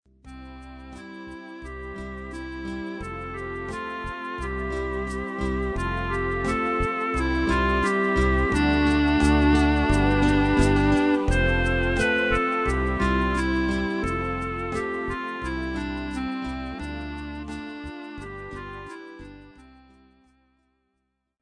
Il frammento propone una breve frase musicale in crescendo, seguita da una seconda frase in diminuendo
cresc_dim.mp3